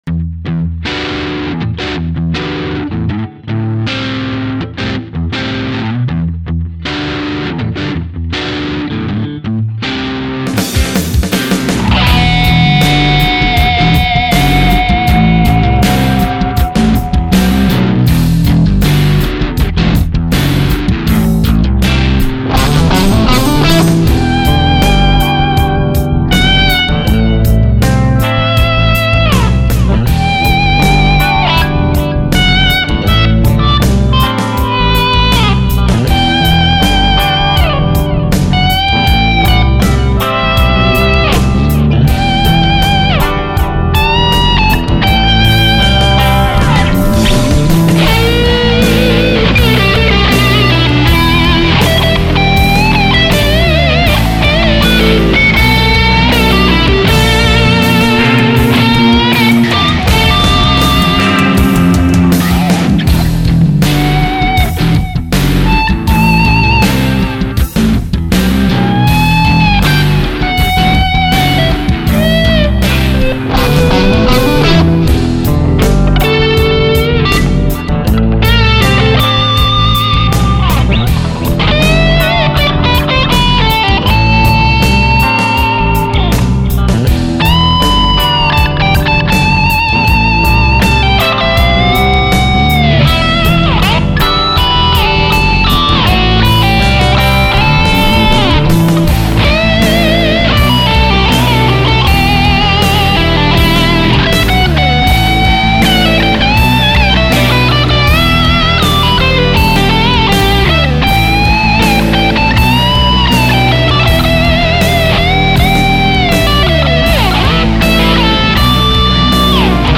aussenjam #39 - octovision - mp3 (in Stereo und in Farbe).
Glücklicherweise ist mein Womanizer immer direkt mit dem Mac verbunden, ich muß nur ein Gitarre einklinken und kann schon fast loslegen.
Also schnell die Warmoth Strat mit den Womanizer verkabelt und zum ersten mal seit 10 Tagen wieder in das Backing reingehört und dabei schnell ausgesteuert und nach der Tonart geschaut.
Das ist also ein richtiger First-Take, beim Einspielen am Backing orientiert, halt so, wie man das bei einer Session auch machen würde, wenn man bei einem Stück mitspielen würde, welches man nicht kennt.